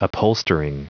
Prononciation du mot upholstering en anglais (fichier audio)
upholstering.wav